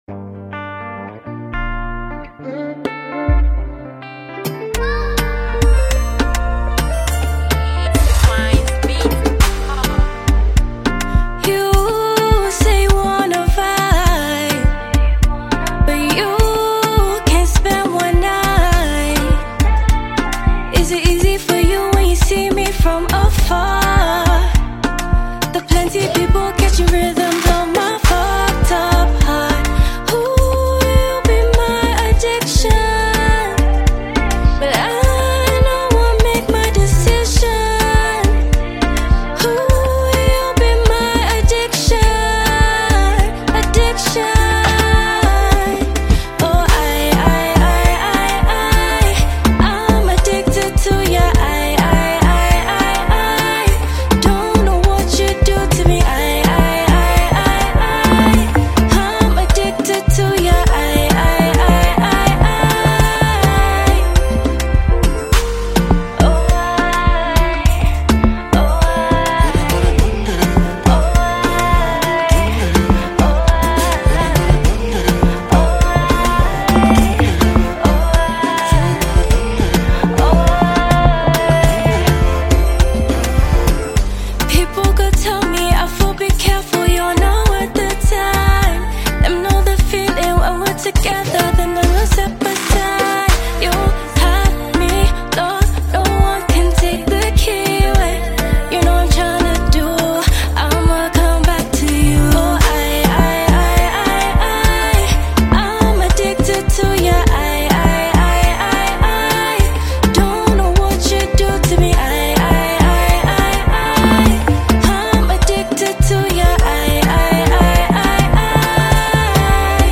Ghana Music
Ghanaian afrobeat sensational musician